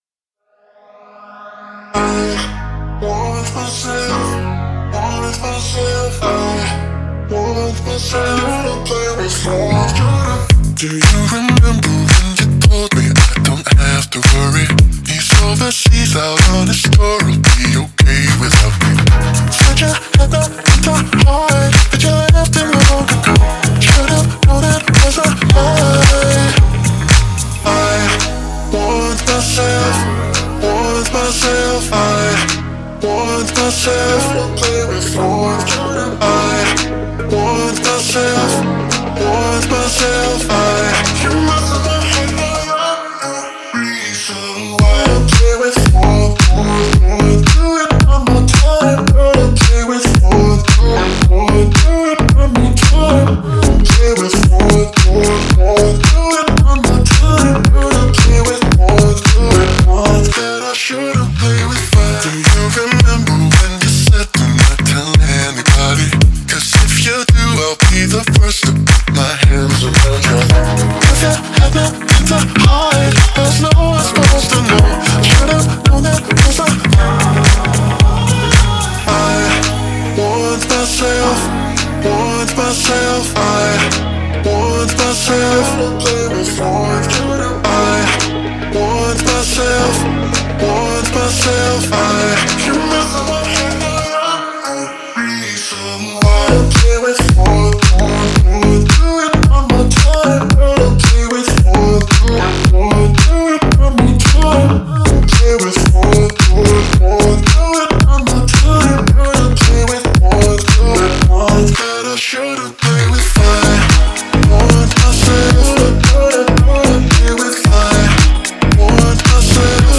смешивая нежные вокалы с экспериментальными битами